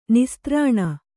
♪ nistrāṇa